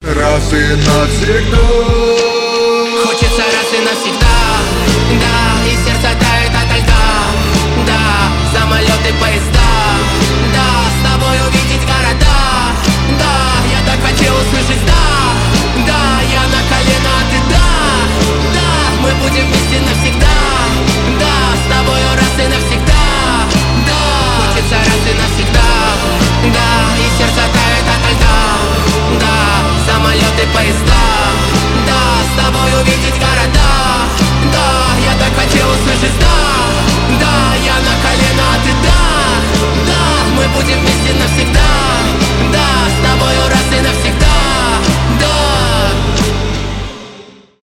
dance pop
танцевальные